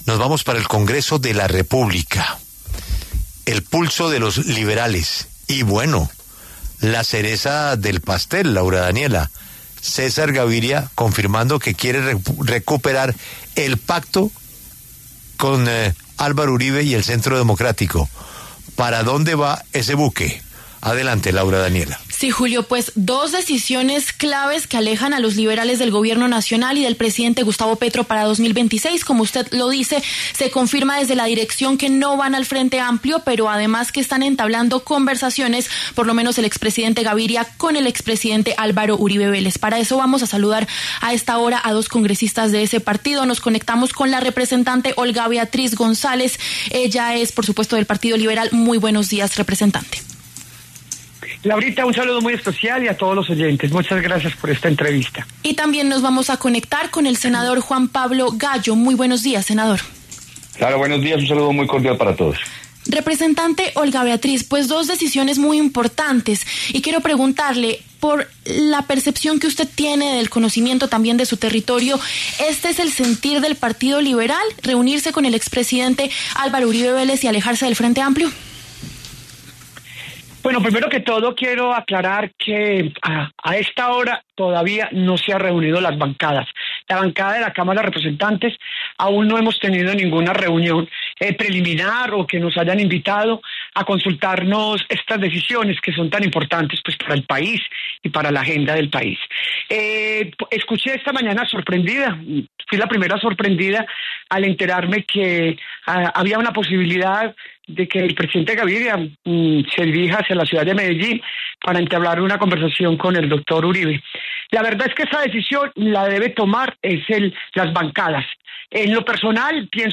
Los congresistas liberales, la representante Olga Beatriz González, de tendencia progresista, y el senador Juan Pablo Gallo, del sector gavirista, pasaron por los micrófonos de La W.